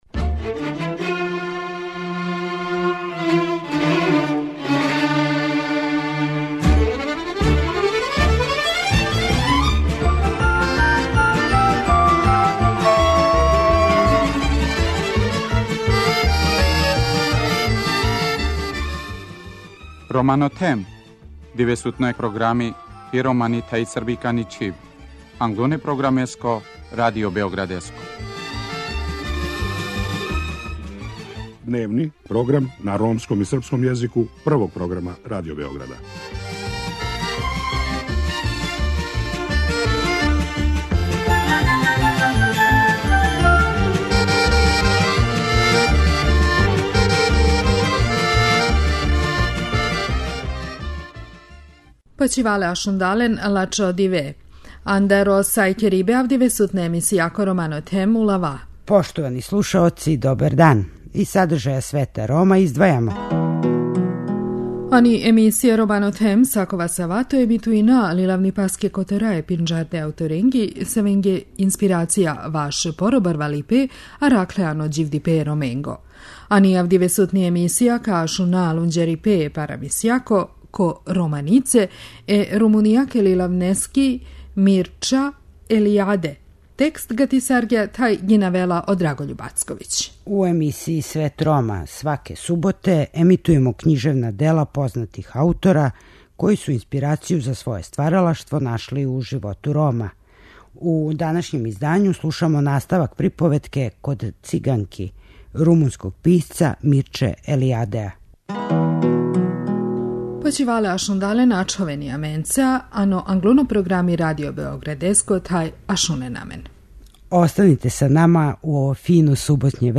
У данашњем издању слушамо наставак приповетке "Код Циганки" румунског писца Мирче Елијадеа.